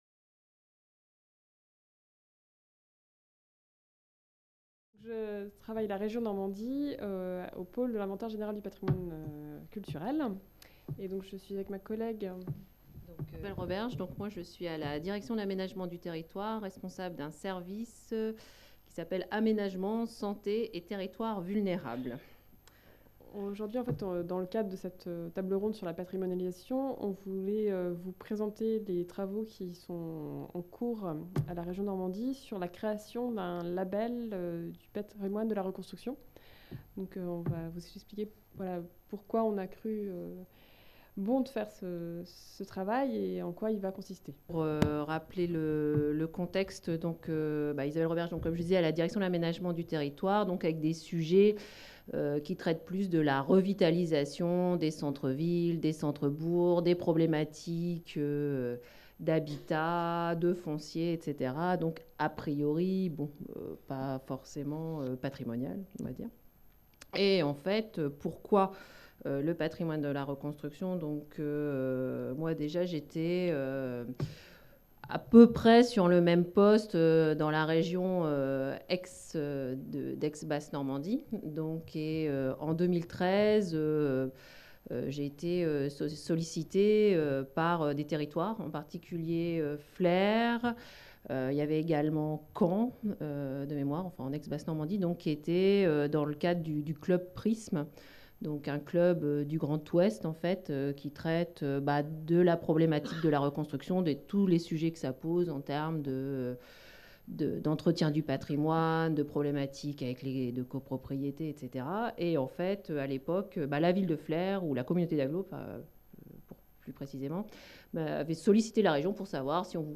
Cette table ronde hors les murs intitulée La patrimonalisation de l'art d'après-guerre : villes, entreprises, musées a eu lieu à l'Université Caen Normandie